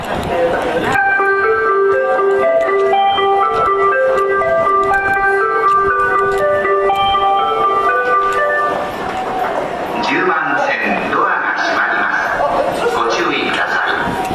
ただ、京成線・新幹線・宇都宮線・高崎線の走行音で発車メロディの収録は困難です。